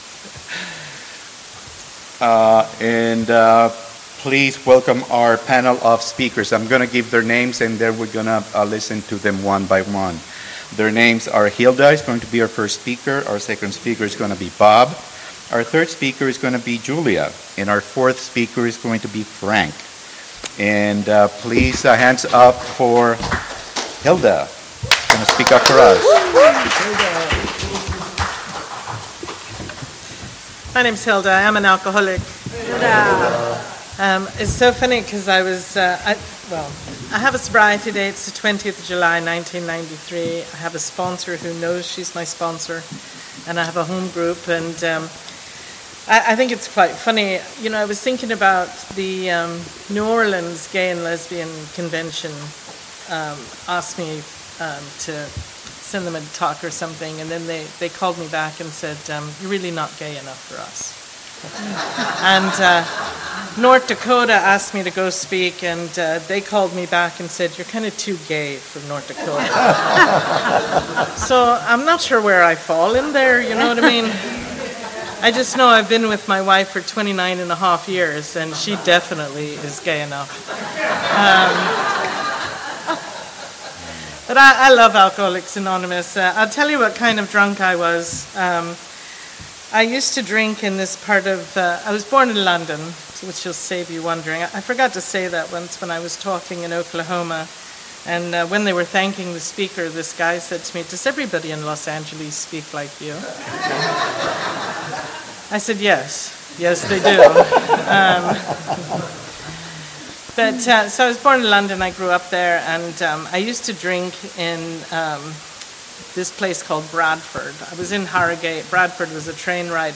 Encore Audio Archives - 12 Step Recovery LGBTQ PANEL – AA – 50th MAAD DOG DAZE 2024